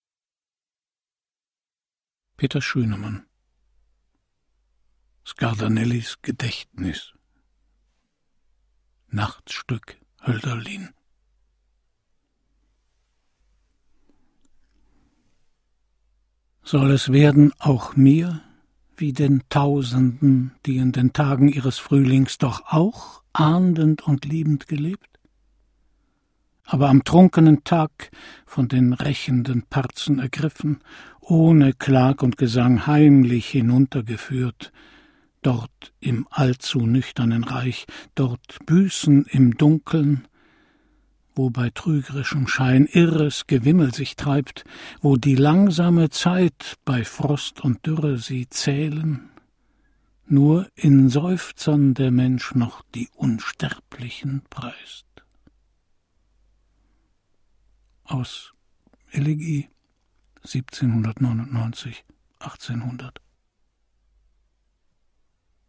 Christian Brückner (Sprecher)